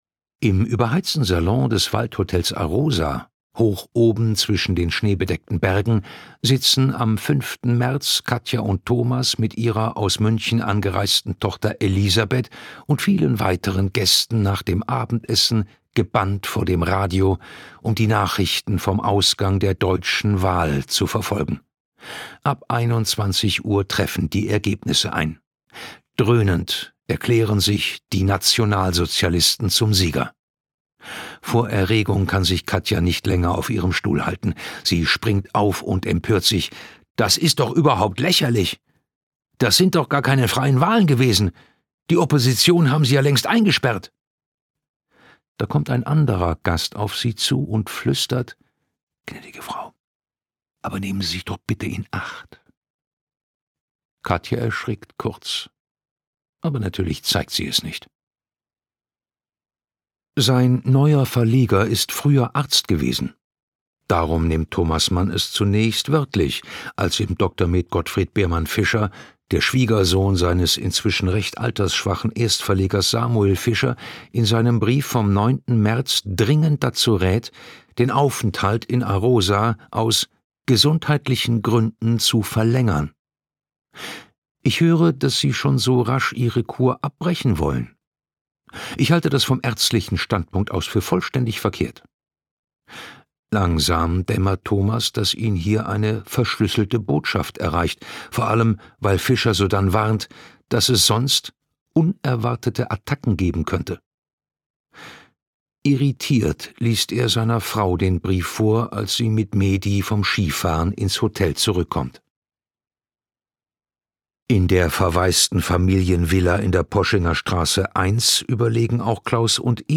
Gekürzt Autorisierte, d.h. von Autor:innen und / oder Verlagen freigegebene, bearbeitete Fassung.
Hörbuchcover von Wenn die Sonne untergeht: Familie Mann in Sanary